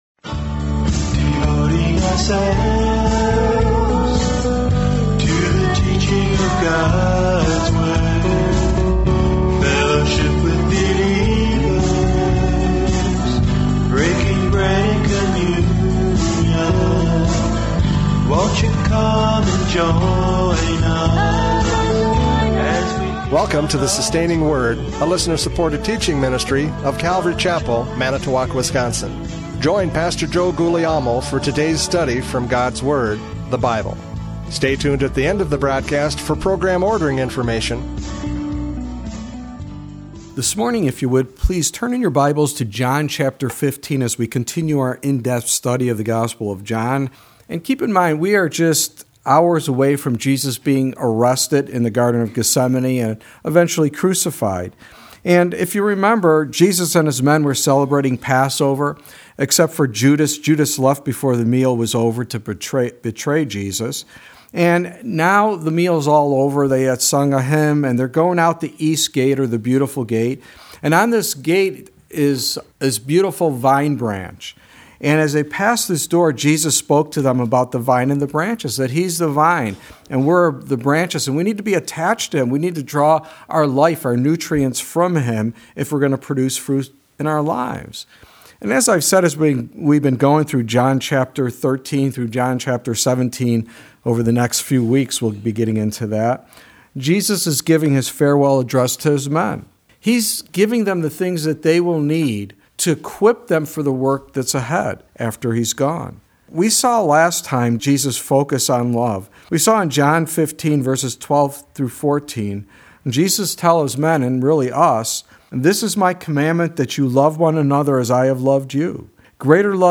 John 15:18-27 Service Type: Radio Programs « John 15:12-17 Love the Brethren!